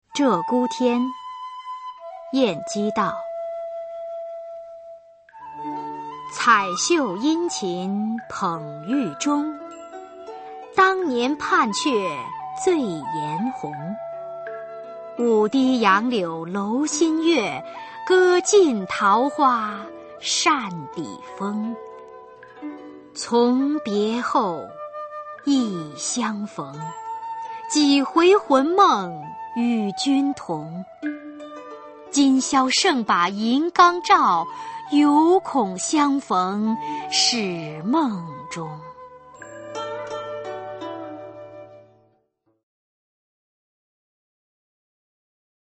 [宋代诗词诵读]晏几道-鹧鸪天·彩绣 宋词朗诵